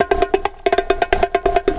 Index of /90_sSampleCDs/USB Soundscan vol.46 - 70_s Breakbeats [AKAI] 1CD/Partition A/27-133PERCS9